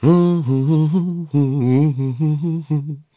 Crow-Vox_Hum.wav